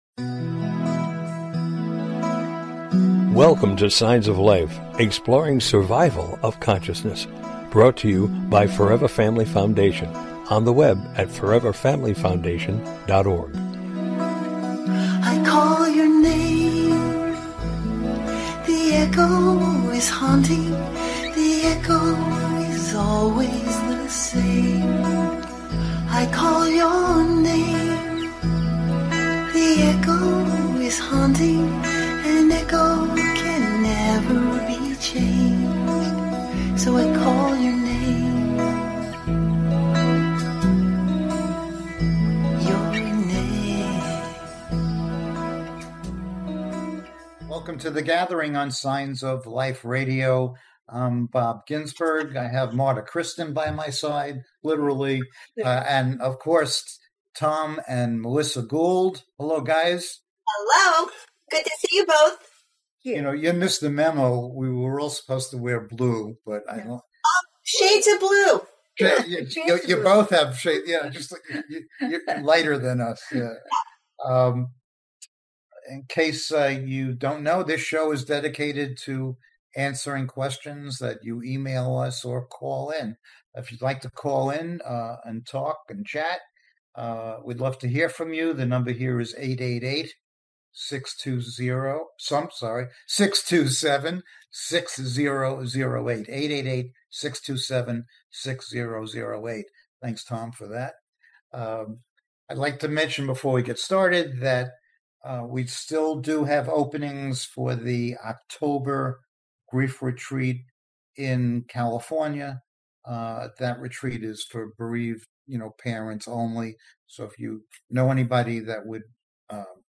Join us this evening for an engaging live discussion on life after death and consciousness!
Tonight our panel will be addressing questions from our listeners that pertain to all matters related to life after death, consciousness, spirituality, etc.